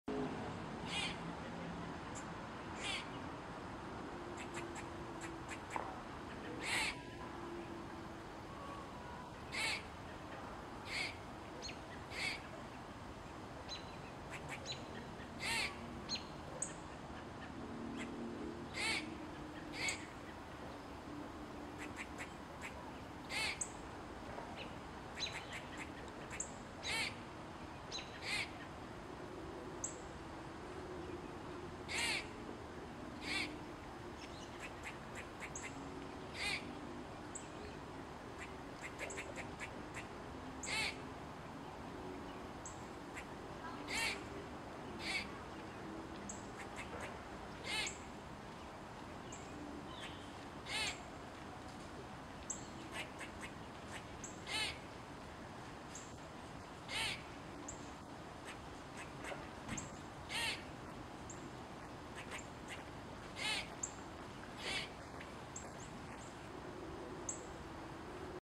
دانلود آهنگ سنجاب در جنگل از افکت صوتی انسان و موجودات زنده
دانلود صدای سنجاب در جنگل از ساعد نیوز با لینک مستقیم و کیفیت بالا
جلوه های صوتی